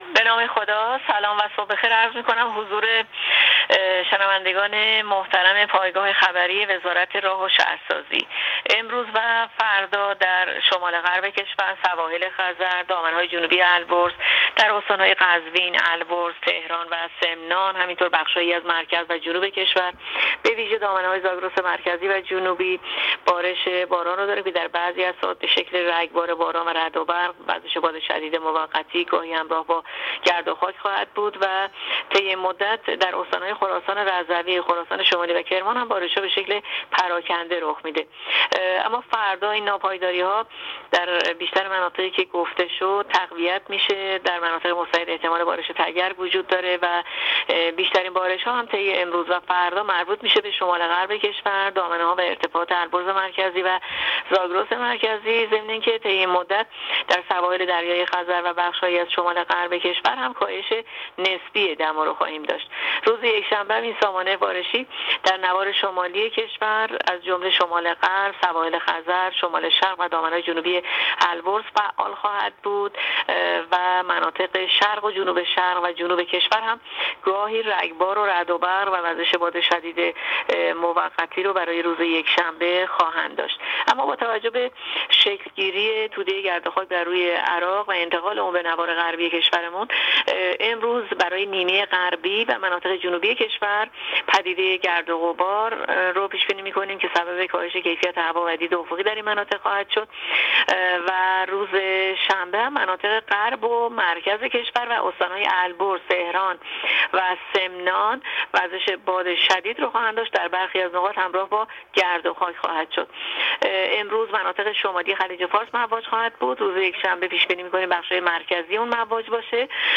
کارشناس سازمان هواشناسی در گفت‌وگو با رادیو اینترنتی پایگاه خبری وزارت راه‌ و شهرسازی، آخرین وضعیت آب‌و‌هوای کشور را تشریح کرد.
گزارش رادیو اینترنتی پایگاه خبری از آخرین وضعیت آب‌‌و‌‌‌هوای شانزدهم اردیبهشت؛